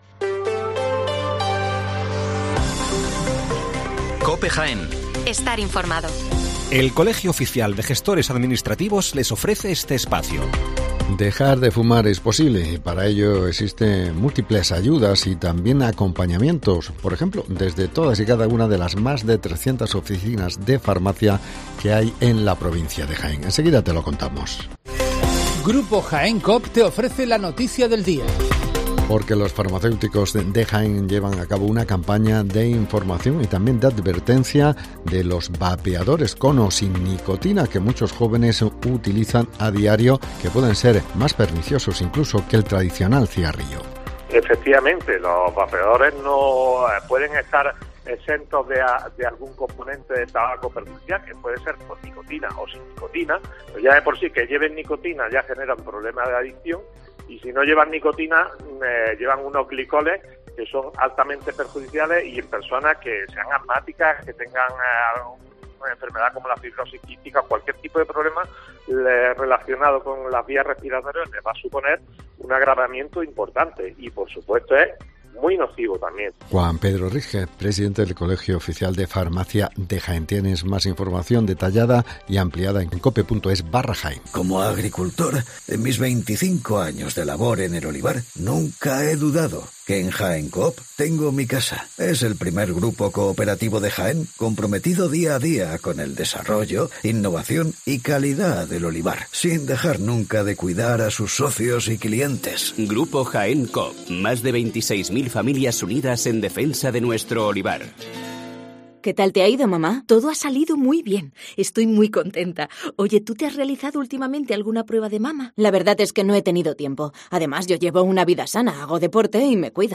Las noticias locales de las 7'55 del 2 de junio de 2023